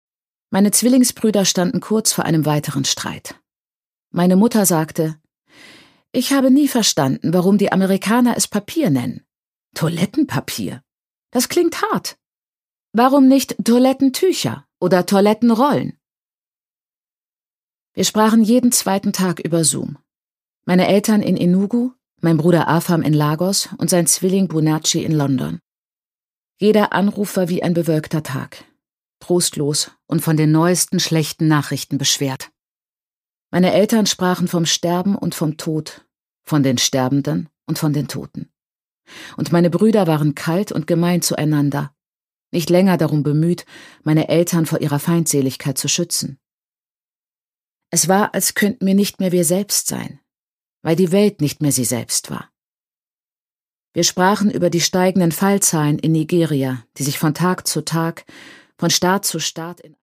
Chimamanda Ngozi Adichie: Dream Count (Ungekürzte Lesung)
Produkttyp: Hörbuch-Download